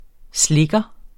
Udtale [ ˈslegʌ ]